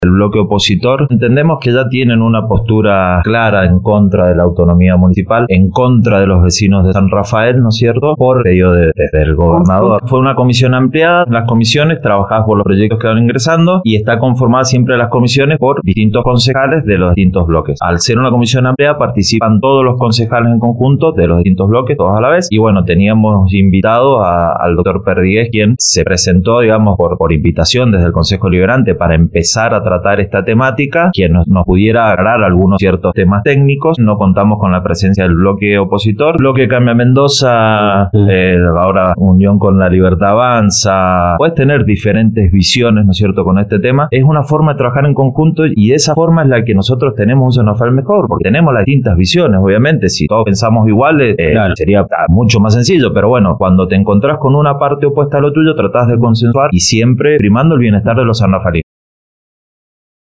mariano-camara-02-concejal-pj-oposicion-a-la-autonomia-municipal-criticas-al-bloque-cambia-mendoza.mp3